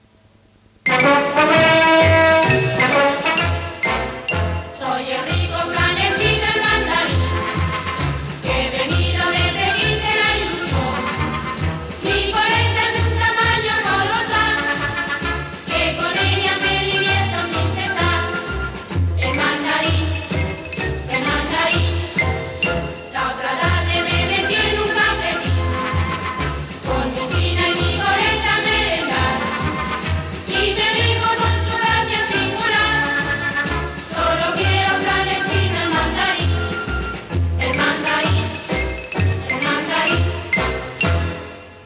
Anunci de Flan Chino Mandarín